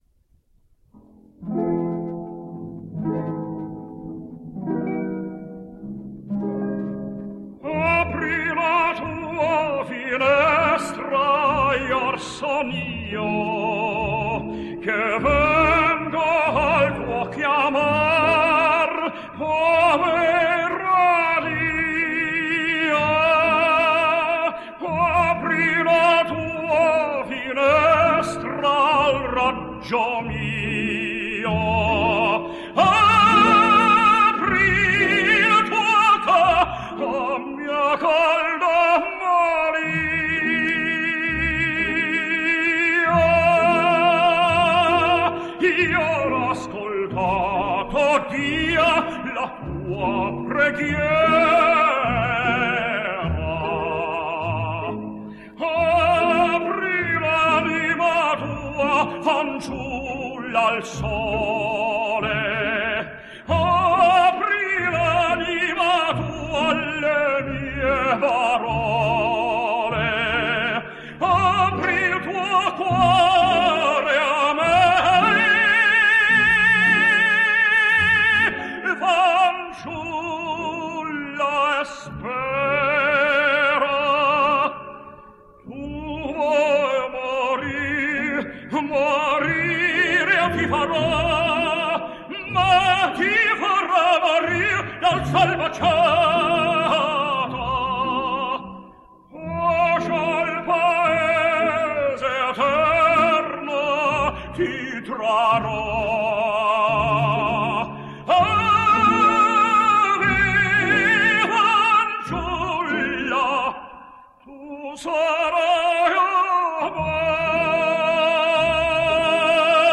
American Tenor.
Here he is singing Apri La Tua Finestra from Mascagni’s opera, Iris.